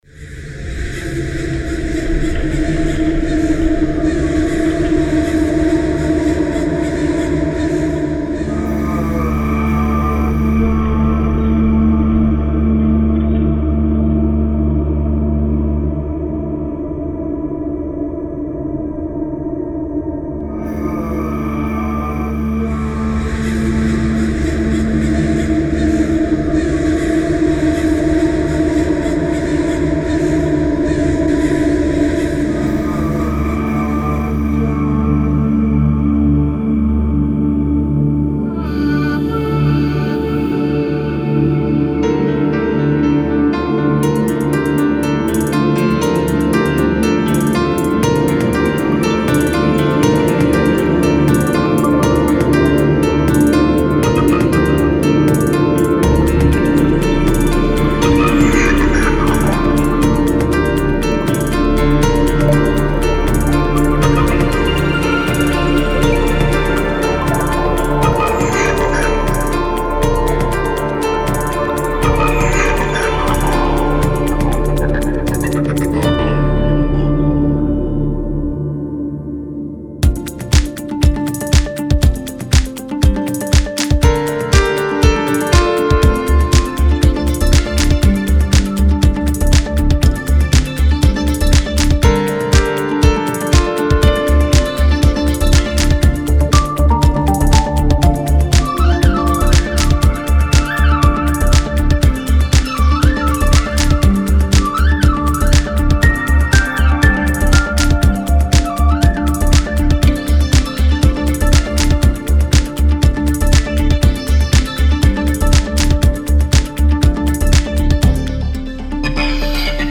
По стилю близко к мелодичному техно.